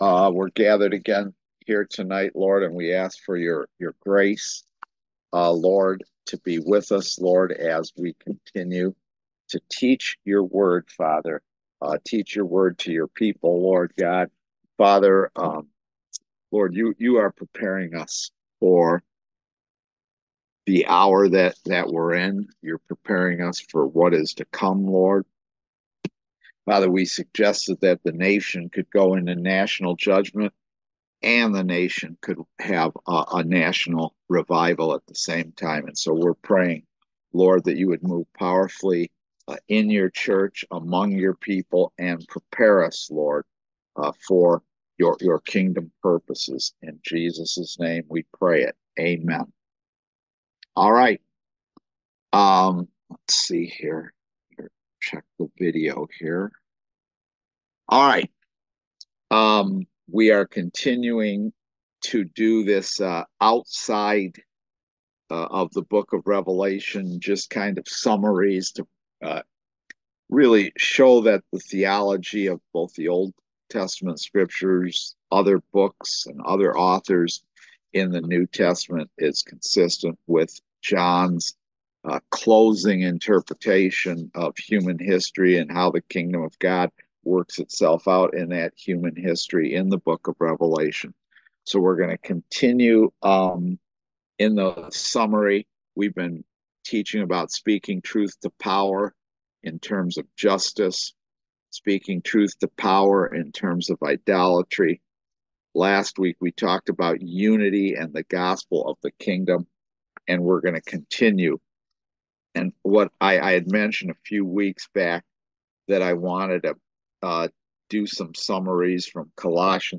Series: Eschatology in Daniel and Revelation Service Type: Kingdom Education Class